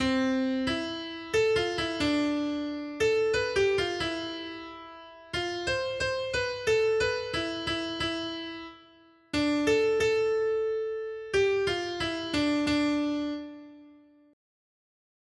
Noty Štítky, zpěvníky ol198.pdf responsoriální žalm Žaltář (Olejník) 198 Skrýt akordy R: Pán je milosrdný a milostivý. 1.